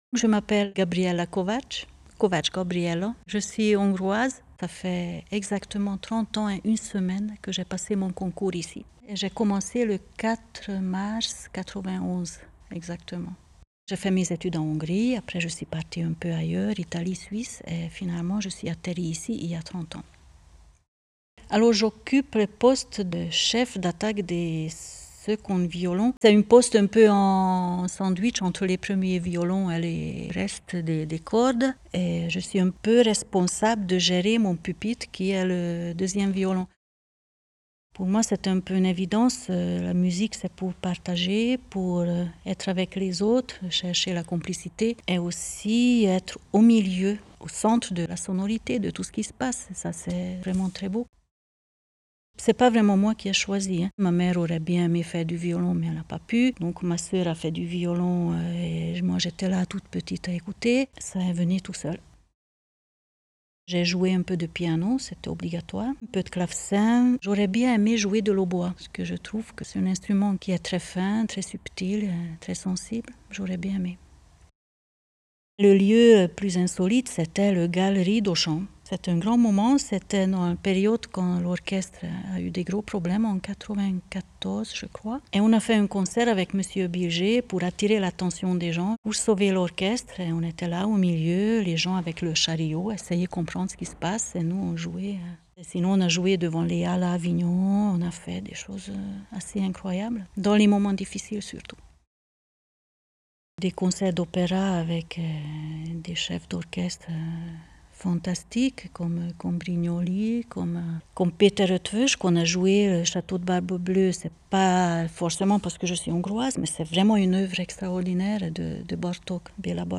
Portrait sonore :